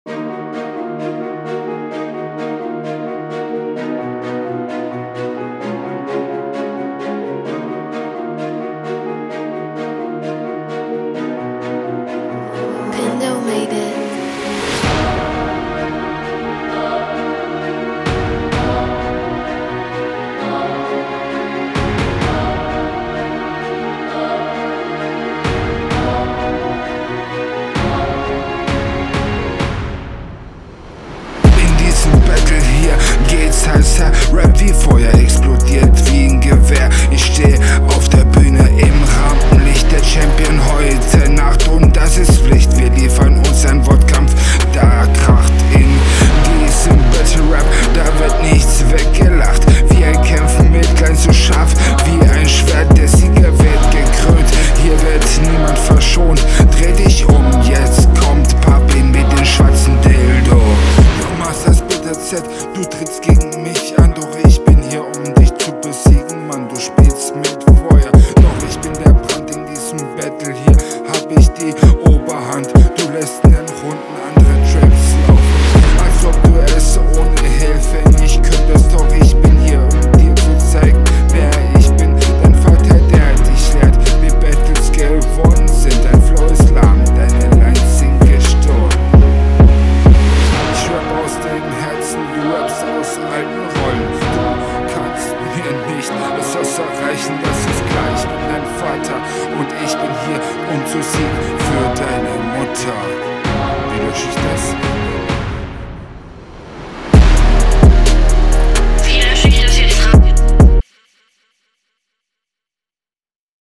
der beat trägt bisschen dick auf ich hab wieder nicht so viel verstanden ist das …